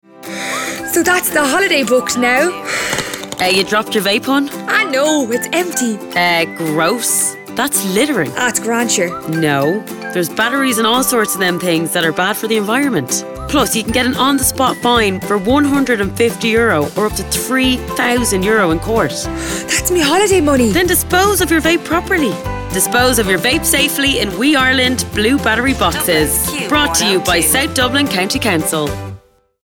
Q9 (i) Radio Advert Q9 Disposable Vapes